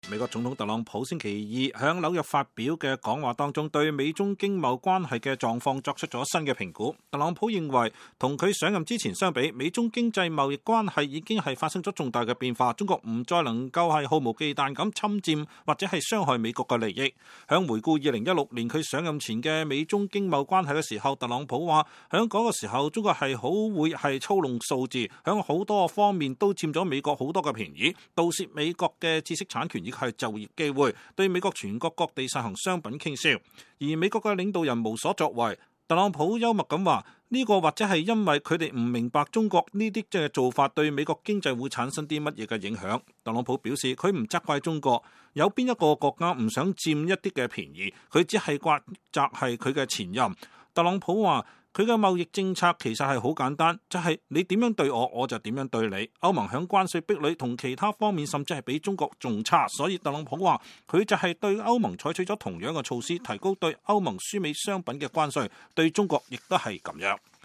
美國總統特朗普2019年11月12日在紐約經濟俱樂部就經貿問題發表講話